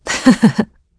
Isolet-Vox_Happy1.wav